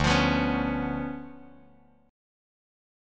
DmM13 chord